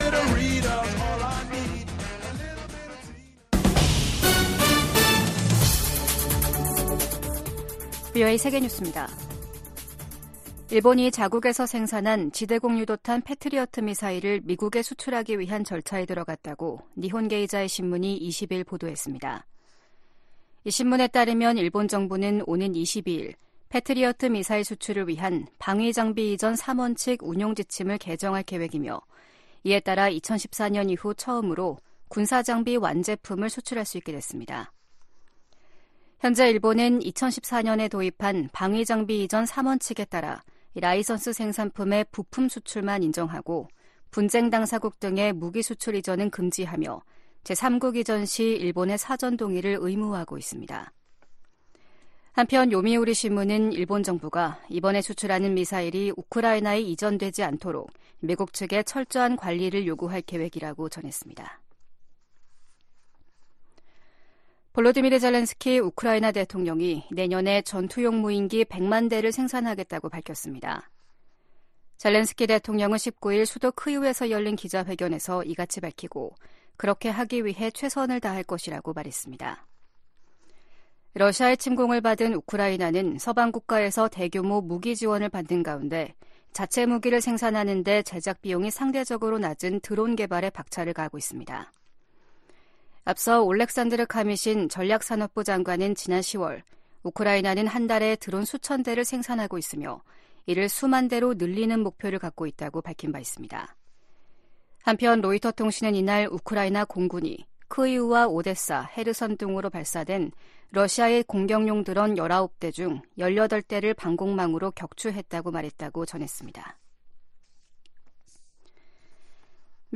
VOA 한국어 아침 뉴스 프로그램 '워싱턴 뉴스 광장' 2023년 12월 21일 방송입니다. 유엔 안보리가 북한의 대륙간탄도미사일(ICBM) 발사에 대응한 긴급 공개회의를 개최합니다. 미 국무부는 중국에 북한의 개발 핵 야욕을 억제하도록 건설적 역할을 촉구했습니다. 북한은 어제(18일) 고체연료 기반의 대륙간탄도미사일 ‘화성-18형’ 발사 훈련을 실시했다며 미국 본토에 대한 핵 위협을 노골화했습니다.